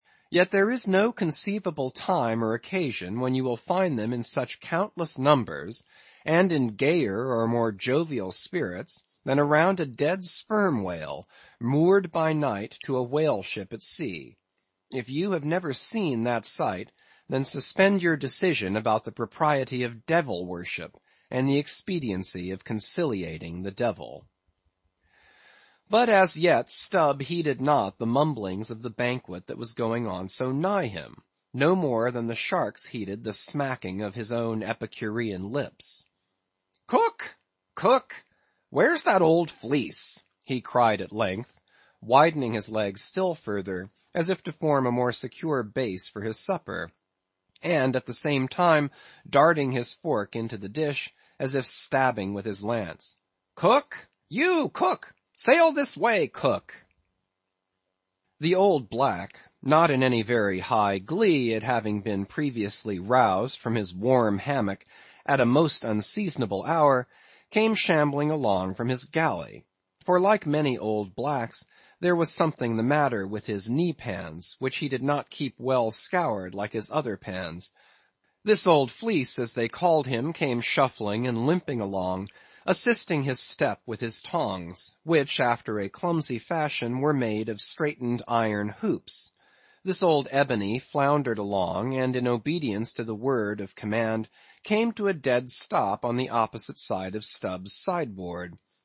英语听书《白鲸记》第614期 听力文件下载—在线英语听力室